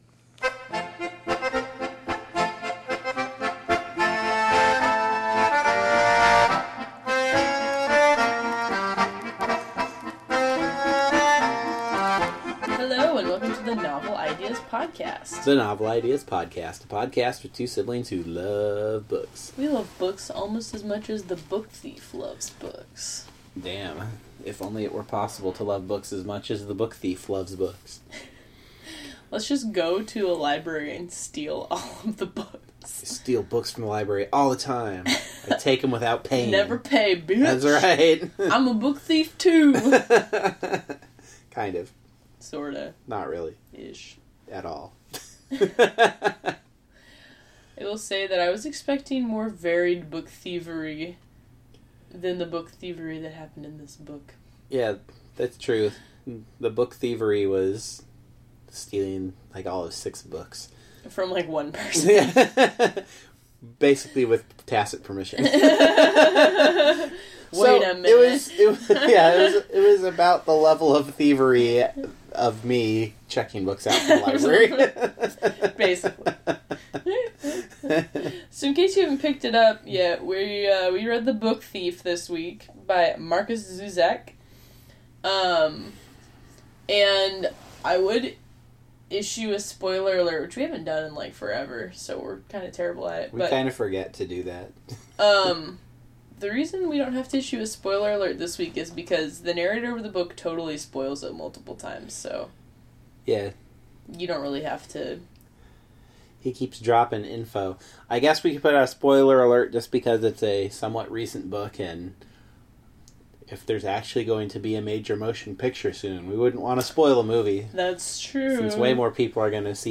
The music bump is “Roses of the South,” a waltz by Johan Strauss, performed on the accordion.
Try to overlook the ambient noise in our studio, primarily generated by a squeaky office chair.